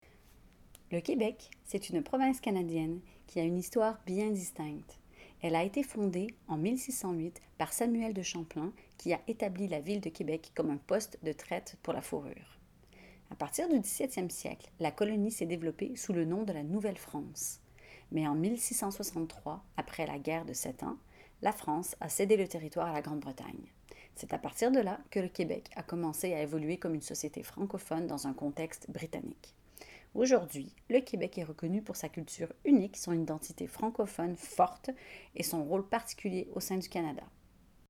12 - 94 ans - Mezzo-soprano